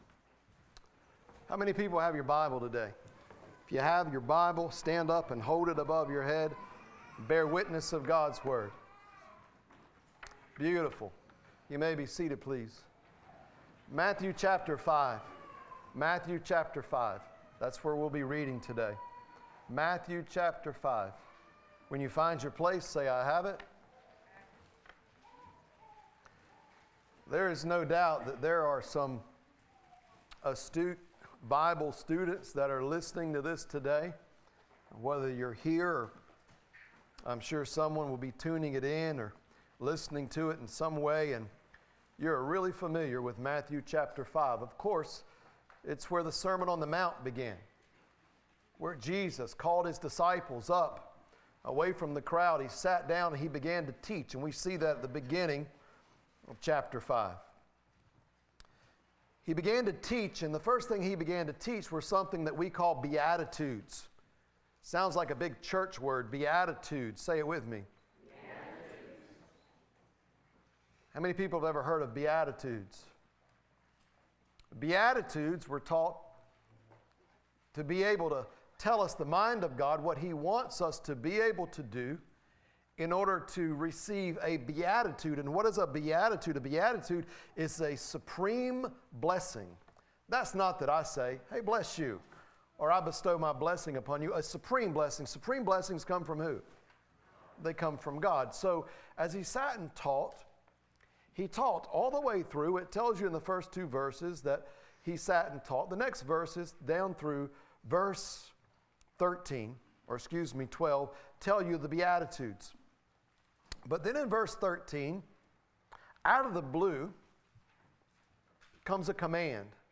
Listen to Sermons - Nazareth Community Church